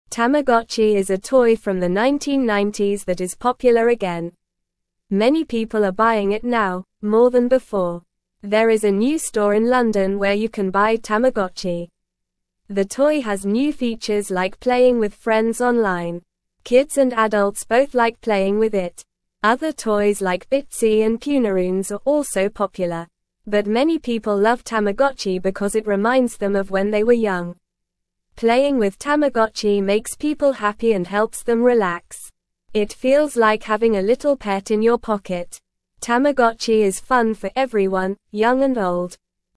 Normal
English-Newsroom-Beginner-NORMAL-Reading-Tamagotchi-Toy-Makes-People-Happy-and-Relaxed.mp3